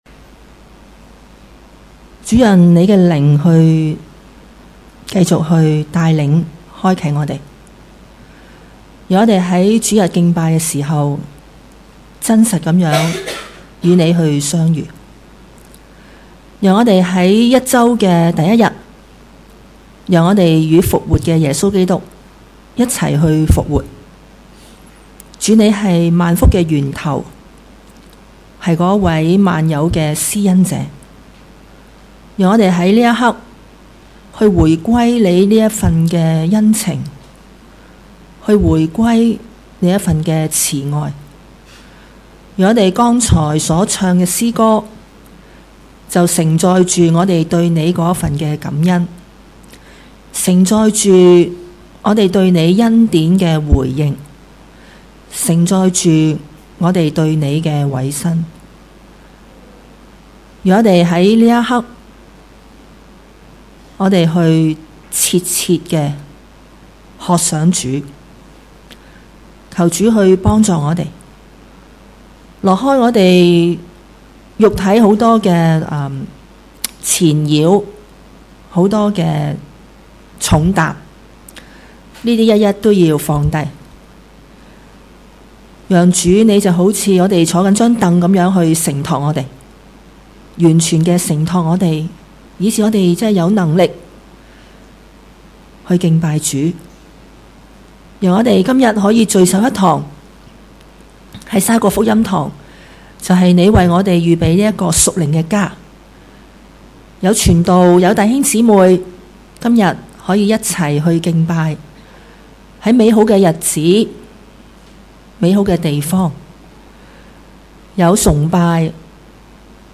主日崇拜講道 – 心被恩感歌頌神